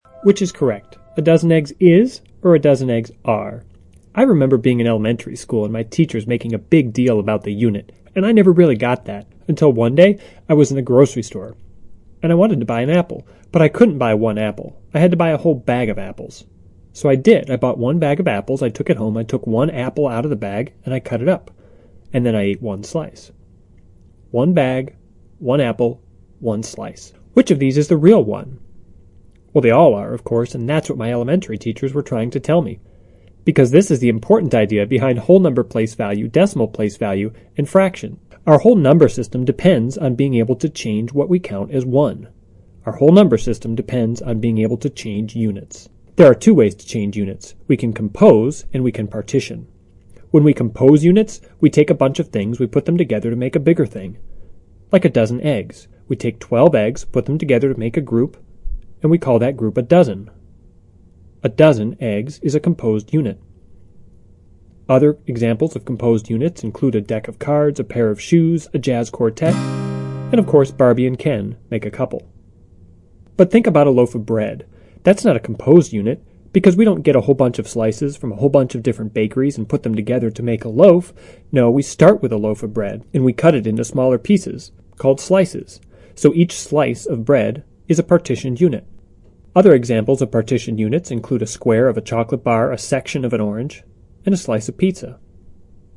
TED演讲:一就是一吗(1) 听力文件下载—在线英语听力室